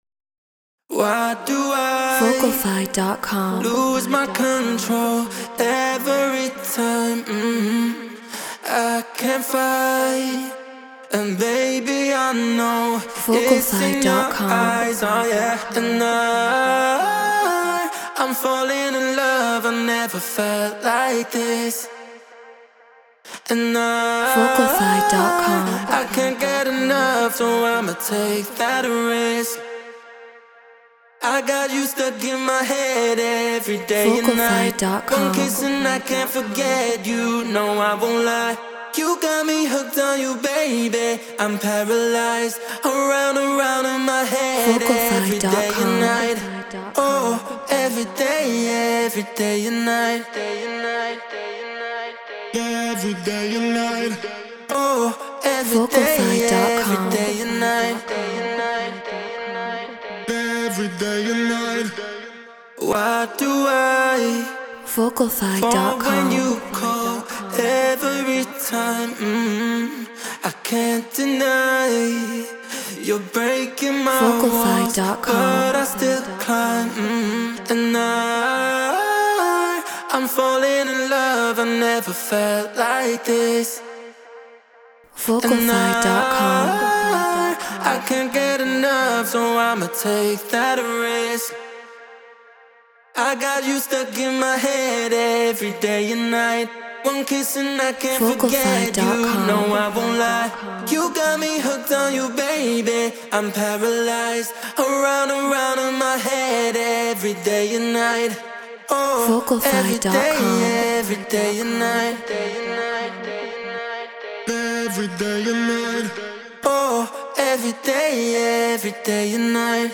House 124 BPM A#min